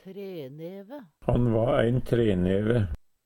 treneve - Numedalsmål (en-US)